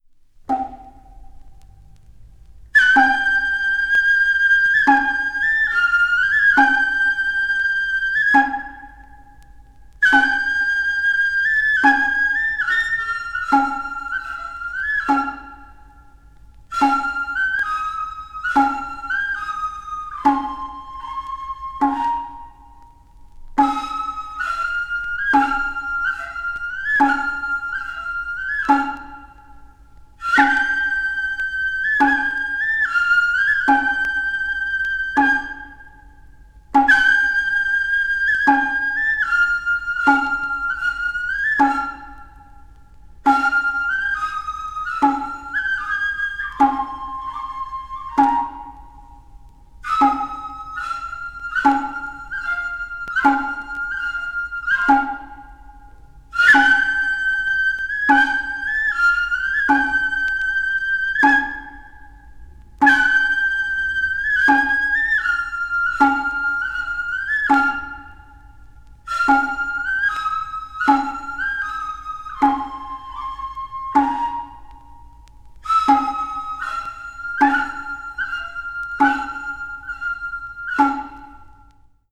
media : EX/EX,EX/EX(わずかにチリノイズが入る箇所あり)
east asia   ethnic music   japan   oriental   traditional